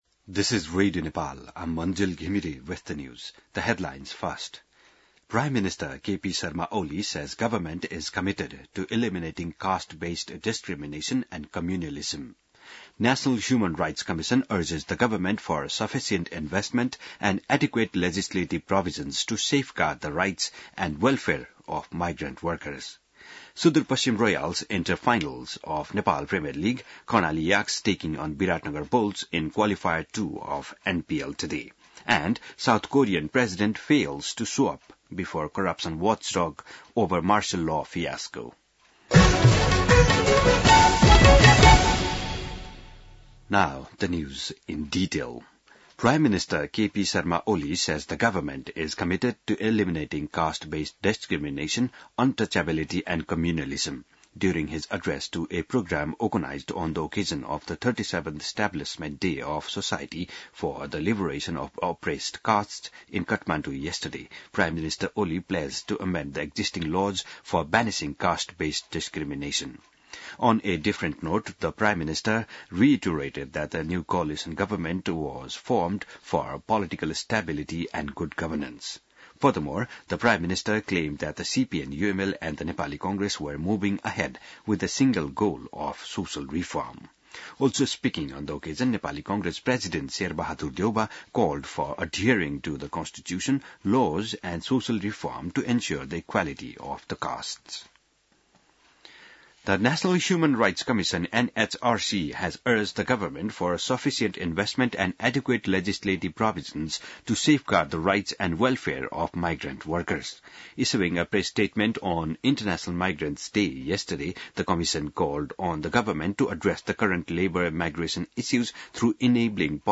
बिहान ८ बजेको अङ्ग्रेजी समाचार : ५ पुष , २०८१